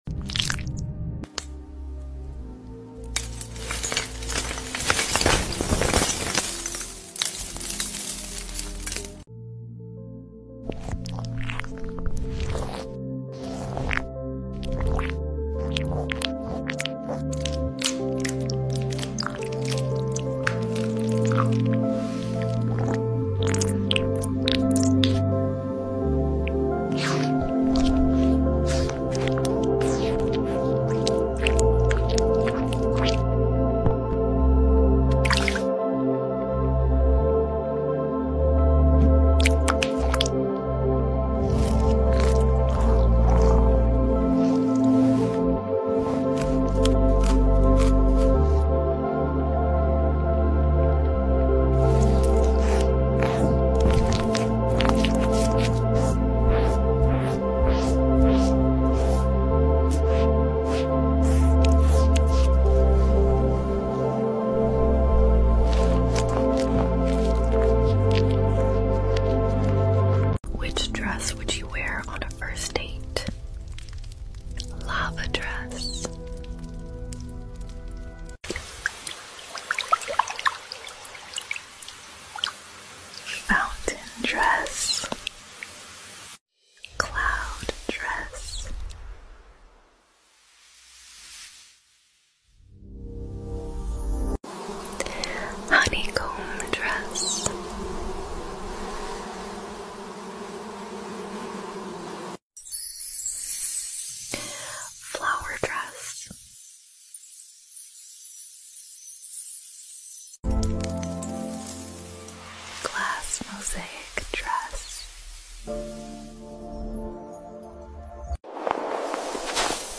ASMR TikTok sound effects free download
In dieser Zusammenstellung haben wir die besten ASMR-Trigger zusammengestellt, die Ihnen helfen, sich zu entspannen, Stress abzubauen und in einen friedlichen Schlaf abzudriften. Von sanftem Flüstern bis zu beruhigenden Geräuschen, dieses Video hat alles!